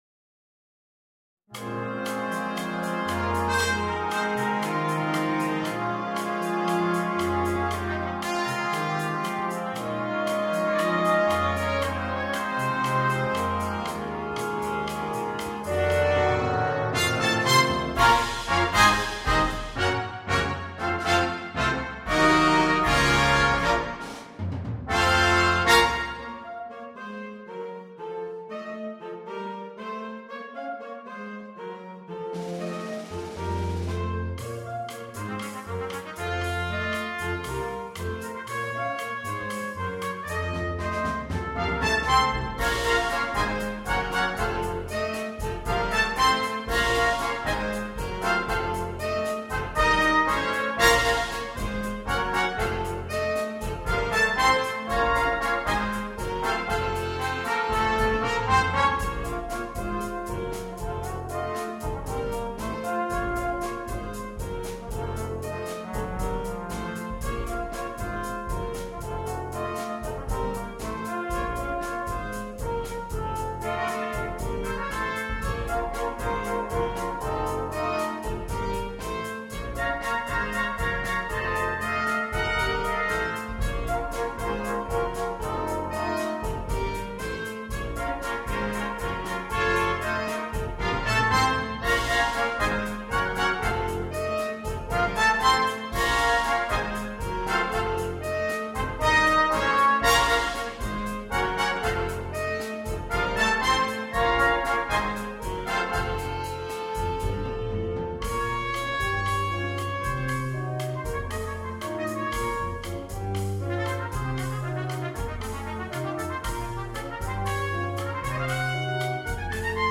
на биг-бэнд